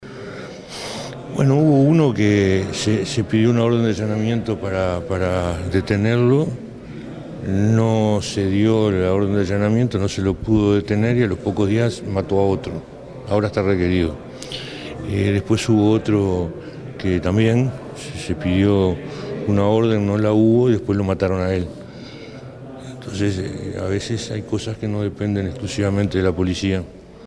El ministro del Interior confirmó en El Espectador que está en curso una investigación administrativa "a partir de la denuncia de que un funcionario tenía una vara extensible que no está autorizada", en el desalojo del Codicen.
Escuche al ministro